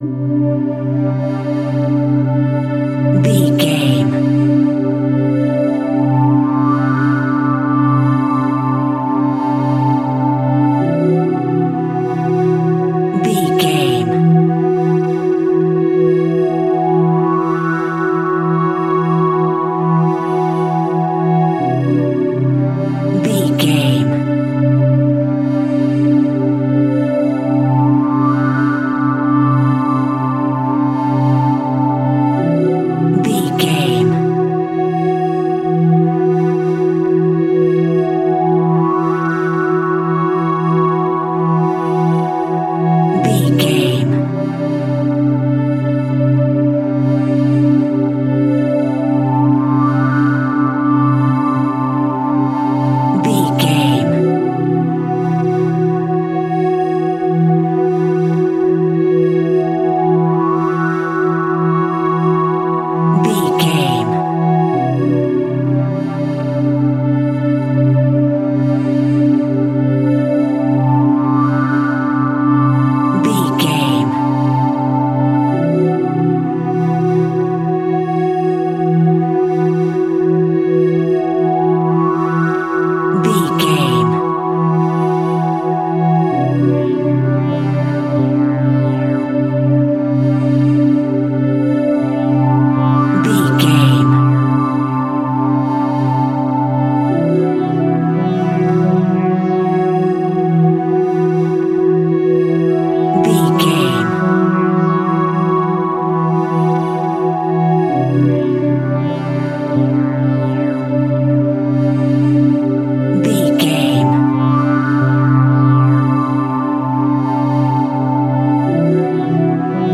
Scary Horror Tones.
Aeolian/Minor
tension
ominous
eerie
synthesizer
Horror Pads
horror piano
Horror Synths